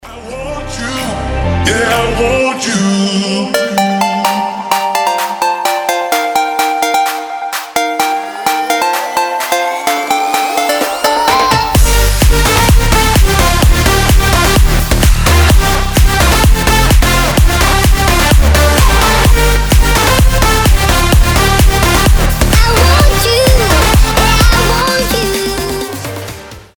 • Качество: 320, Stereo
громкие
EDM
Big Room
progressive house
Громкий хаус с весёлым проигрышем в начале